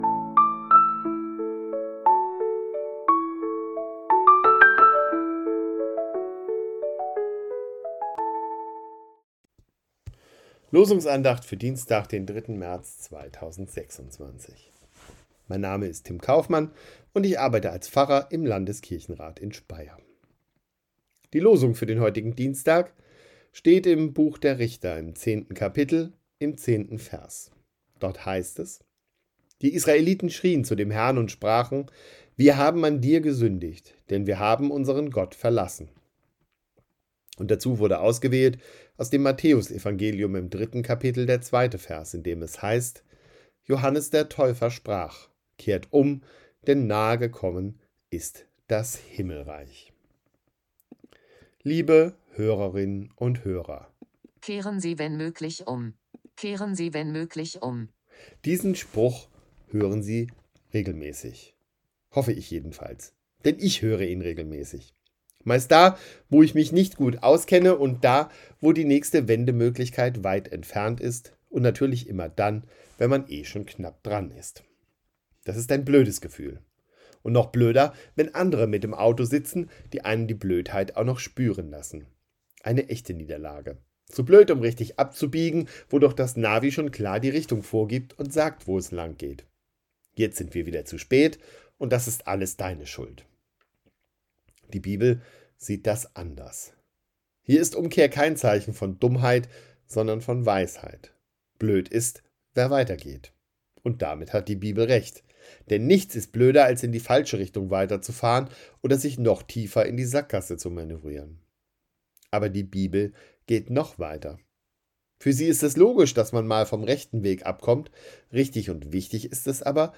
Losungsandacht für Dienstag, 03.03.2026 – Prot.